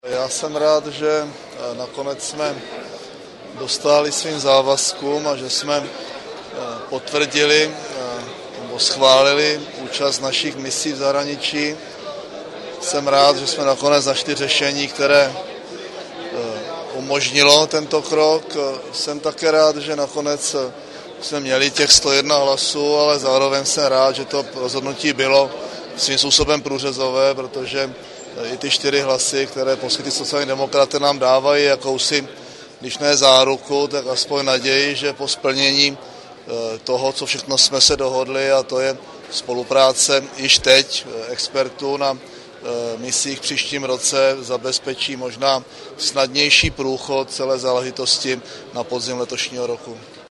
Premiér ke schváleným vojenským misím